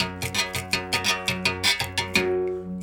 32 Berimbau 04.wav